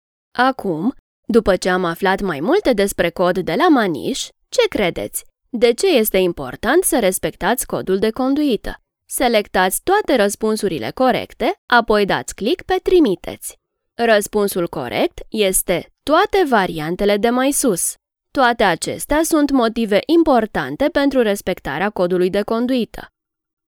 Reliable, Friendly, Warm, Soft, Corporate
E-learning